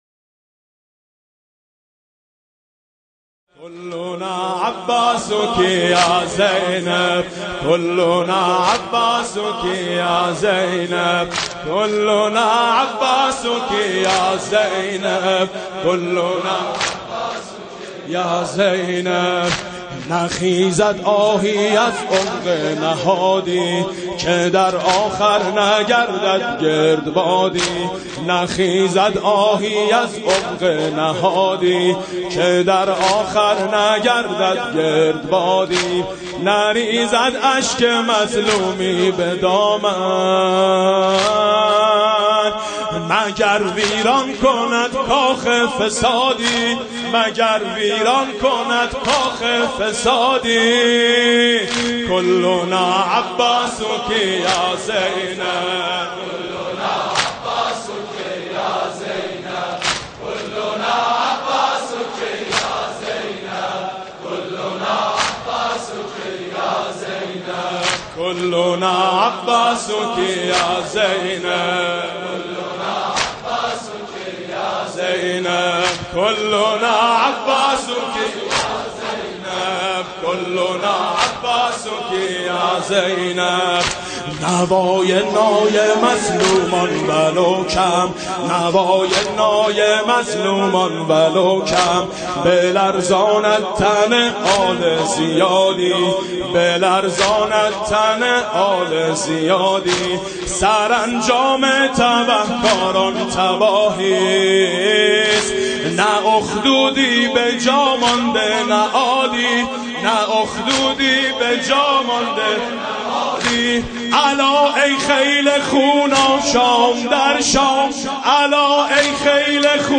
Dirge